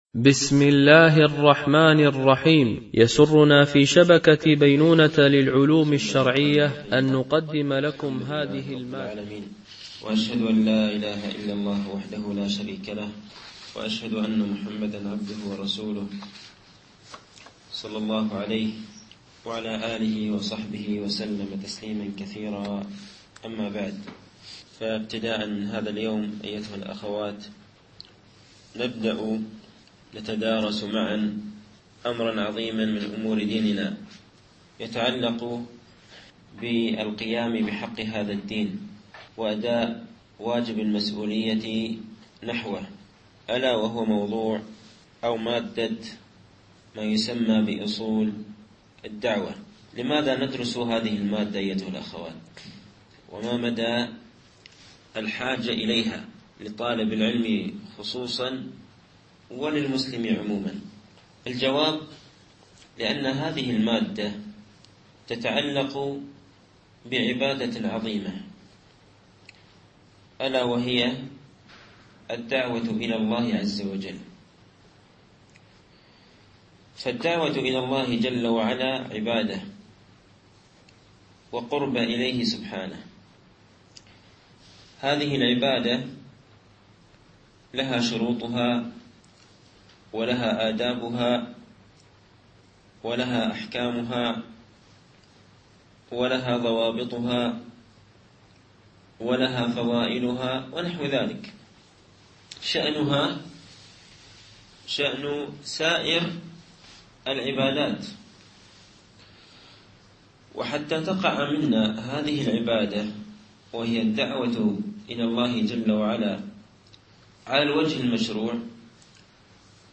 فقه أصول الدعوة إلى الله تعالى - الدرس الأول